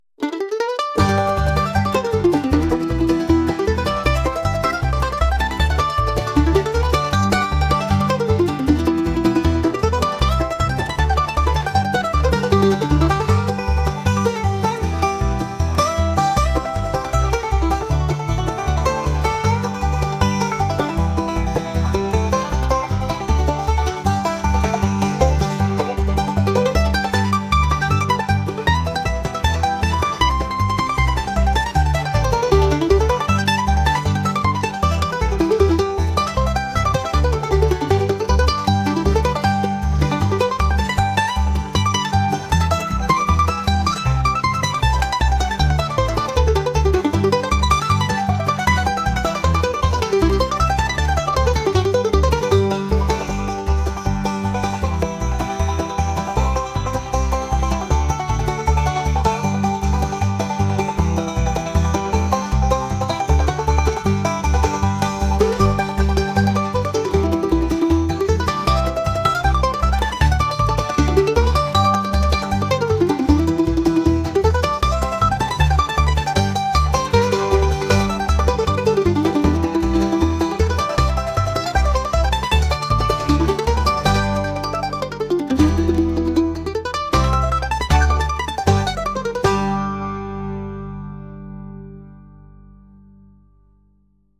country | folk | acoustic